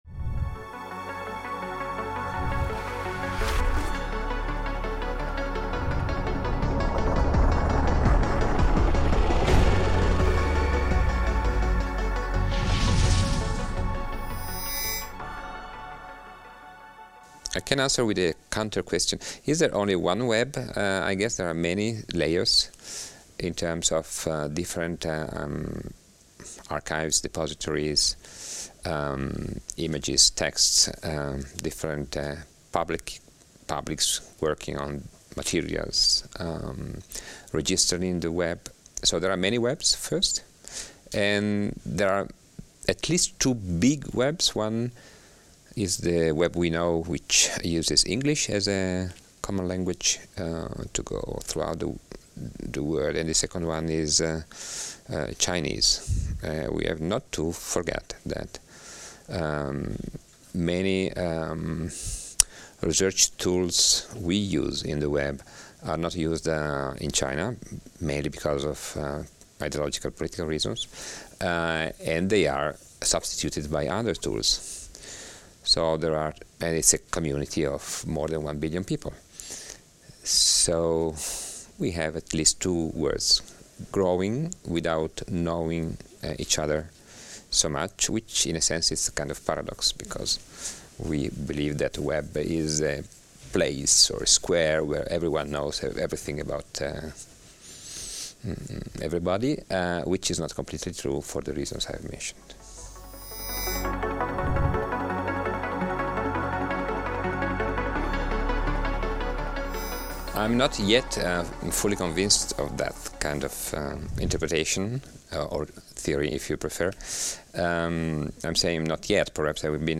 Une série d'entretiens